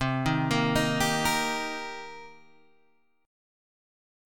C Minor 7th